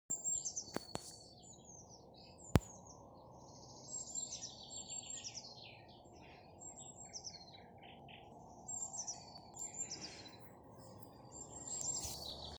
Blue Tit, Cyanistes caeruleus
Ziņotāja saglabāts vietas nosaukumsValles pag
StatusSinging male in breeding season